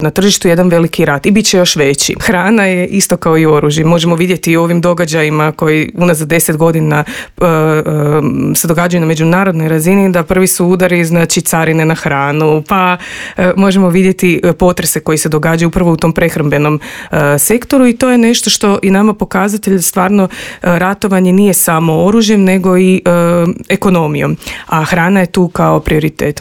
O tome kako spasiti poljoprivrednike, razgovarali smo u Intervjuu Media servisa s voditeljicom Odjela za poljoprivrednu politiku